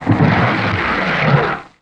MONSTER_Noise_06_mono.wav